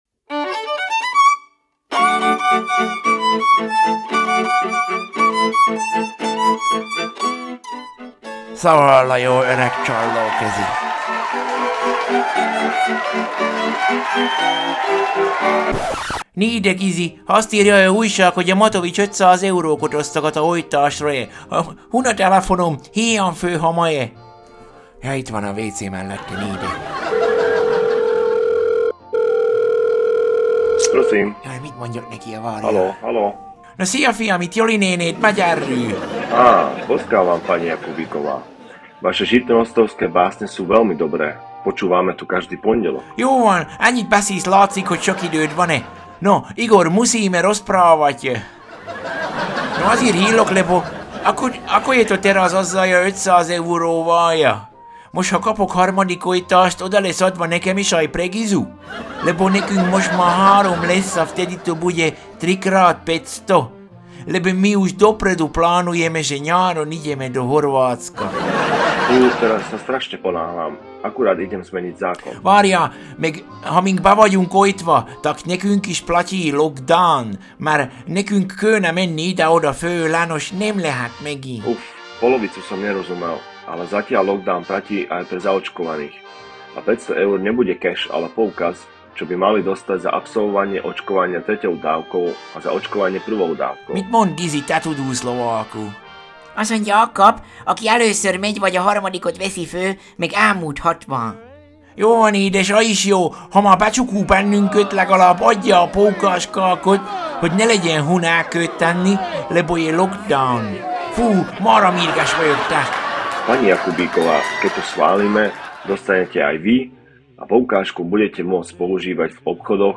Zene: